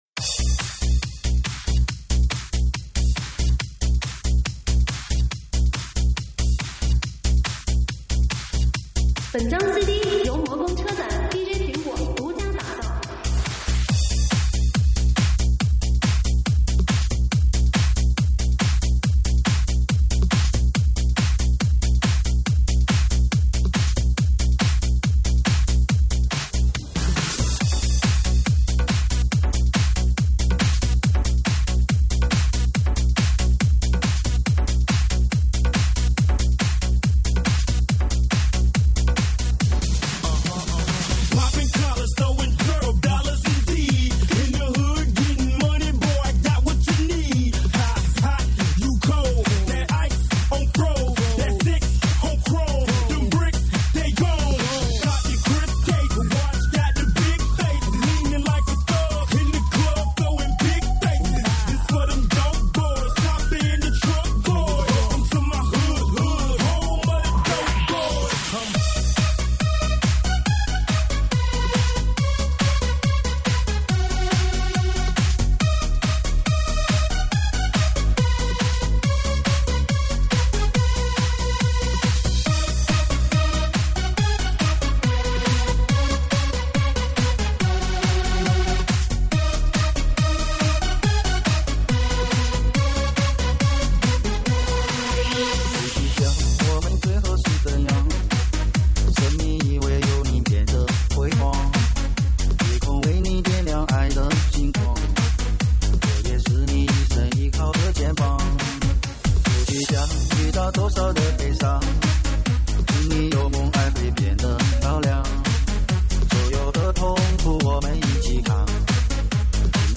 3D全景环绕